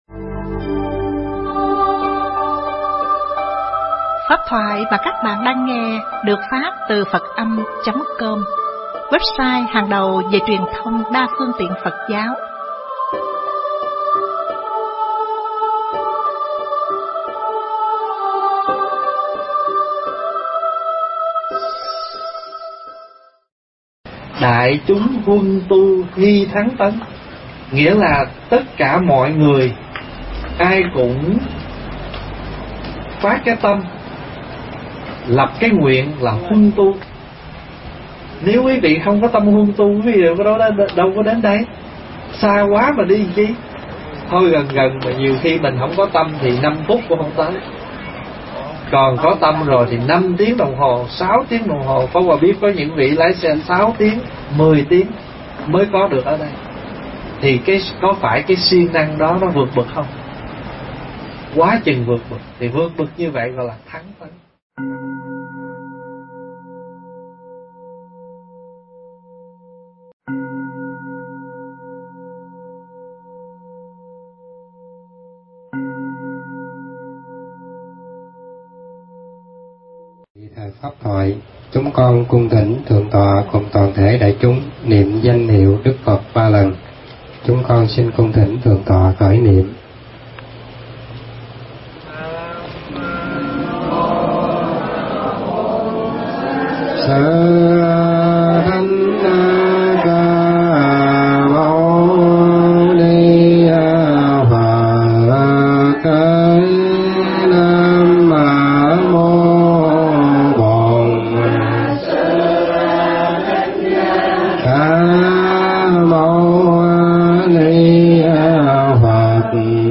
Nghe Mp3 thuyết pháp Huân Tu Thắng Tấn